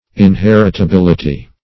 inheritability.mp3